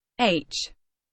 alphabet char sfx